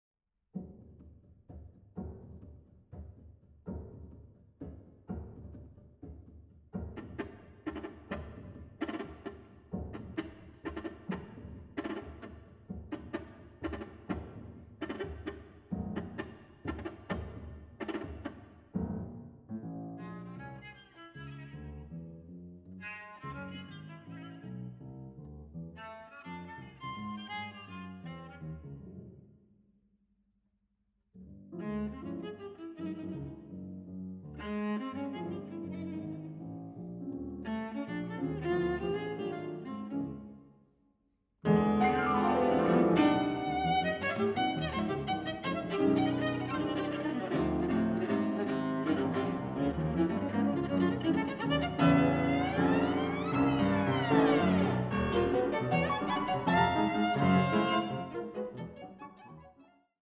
Viola
Piano